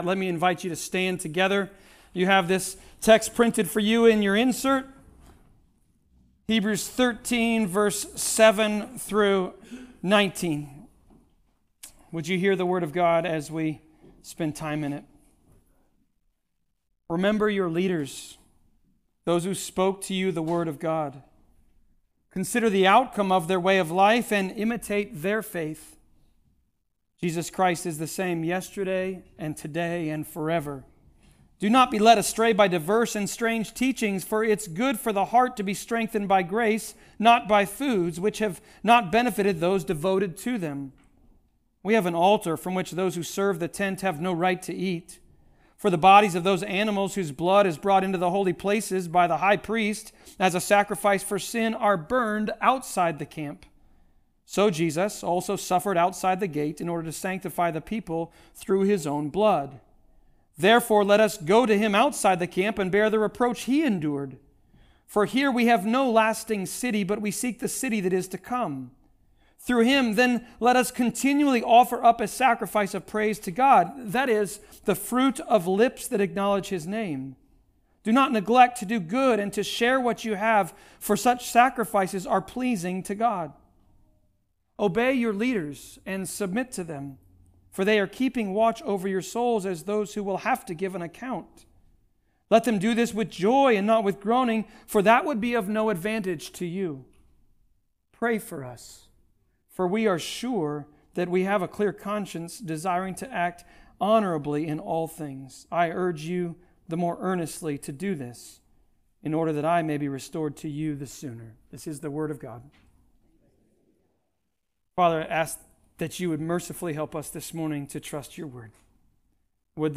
Hebrews 13.7-19 Service Type: Sermons « Things that REMAIN.